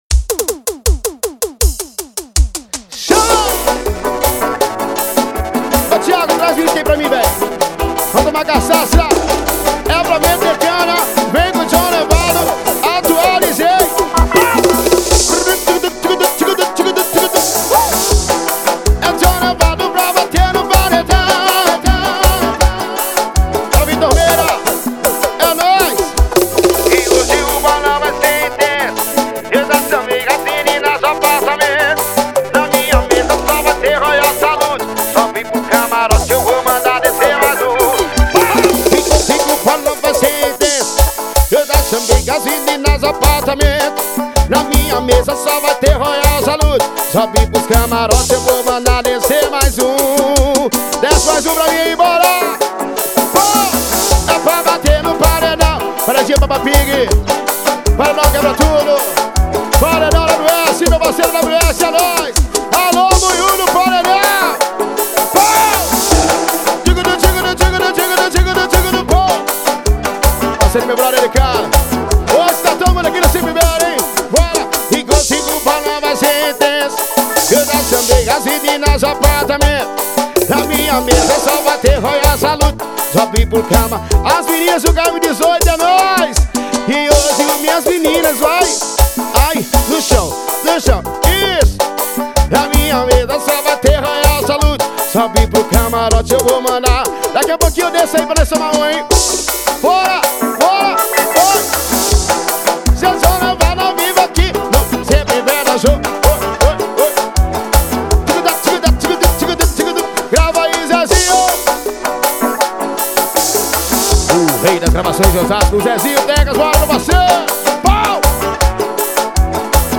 música